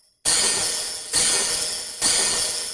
Open closed hat sequence » open closed hat proc 0001
描述：Manual sequence of processed open and closed hihat
标签： bidule closedhat etherreal openhat processed sequence
声道立体声